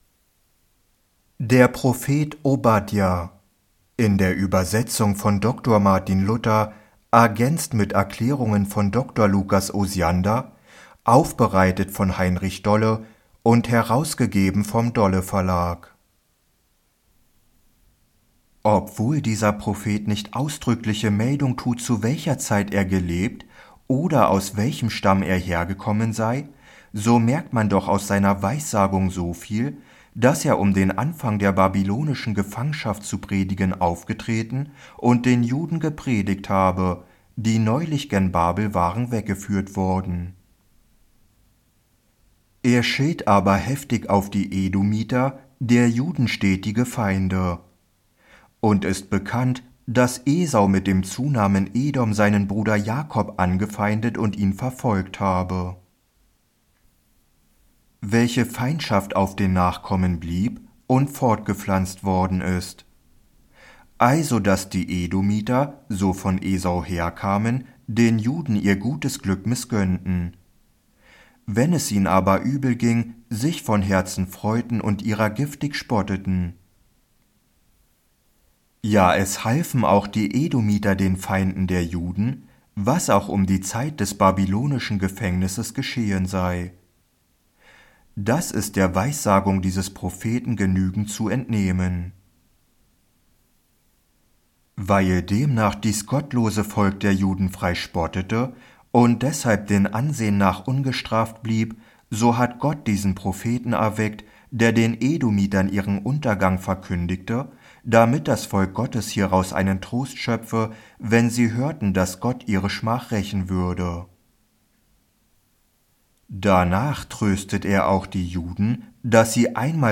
✅ DOWNLOAD: Aus der Osiander-Bibel von 2025, das Buch des Propheten Obadja kostenlos als MP3-Hörbuch herunterladen oder als PDF ansehen.